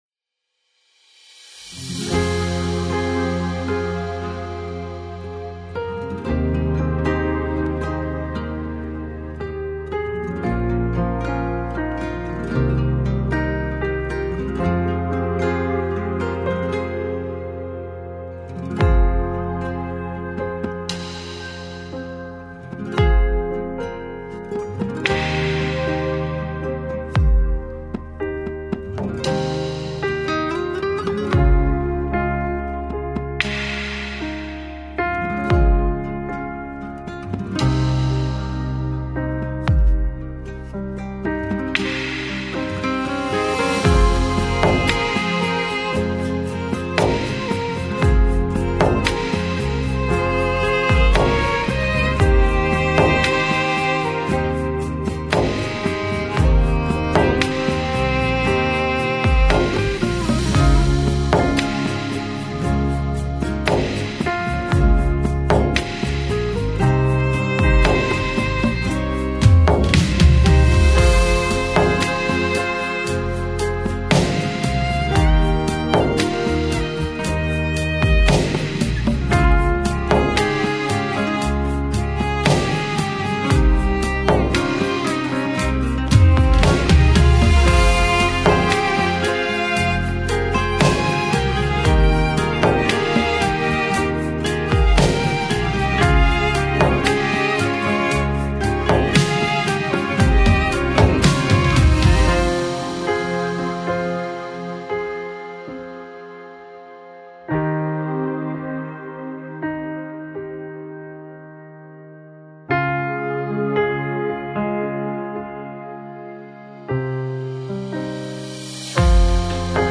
همخوانی فاطمیه سرود فاطمیه